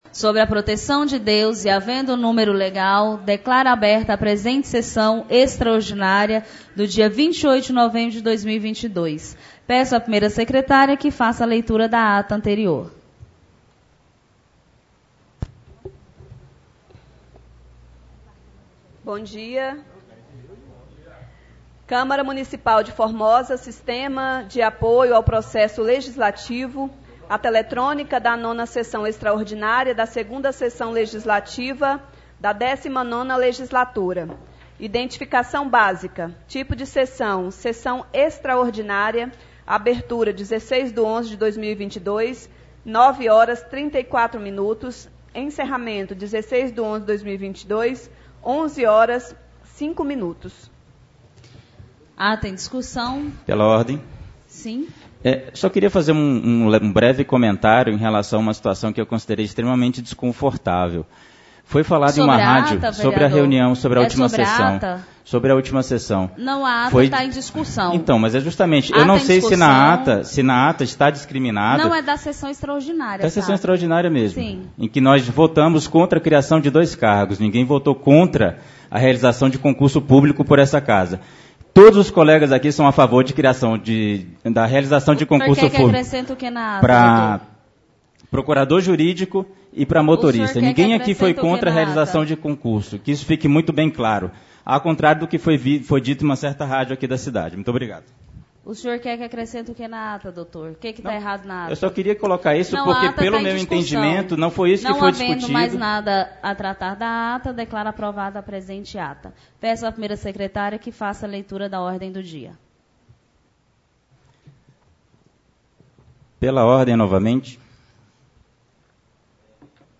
28-11-22 Sessão Extraordinária